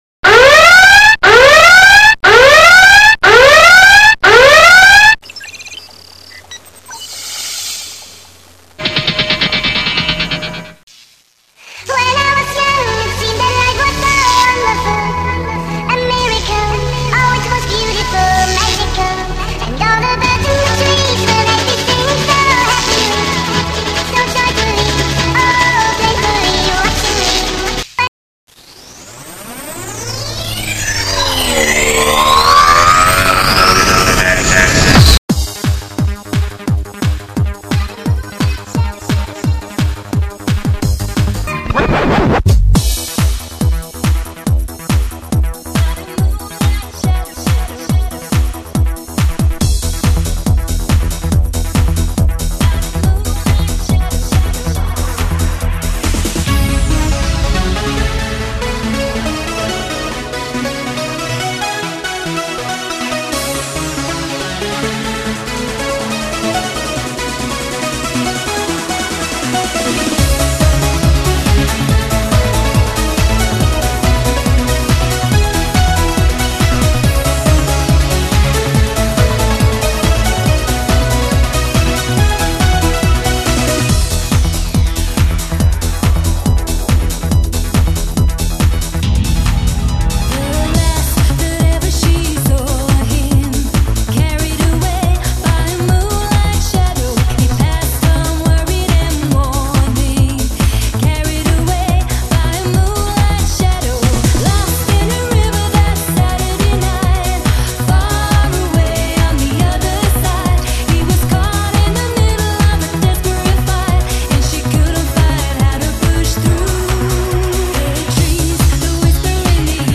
trance&mix